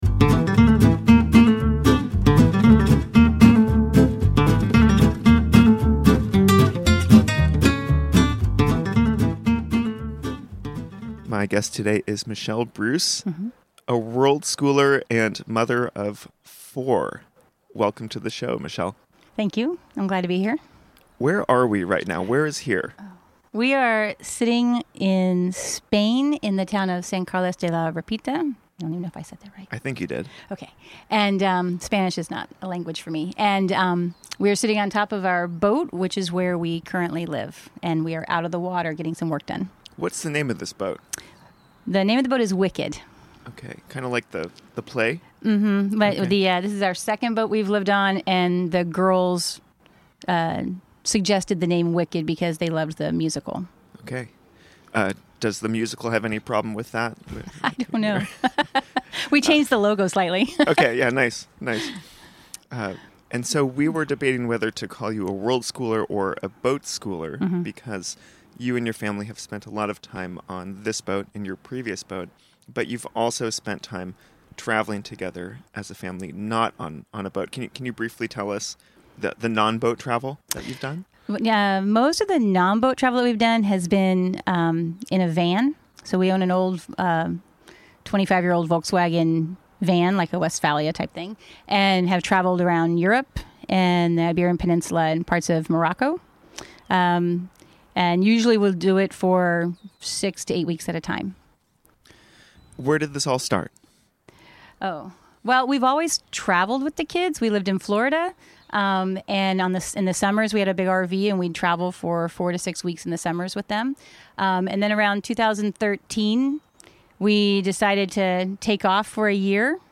Recorded on the boat in Sant Carles